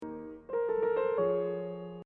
There is a little thing, which is just some passing notes in the theme, which will play a pretty big part in the whole movement.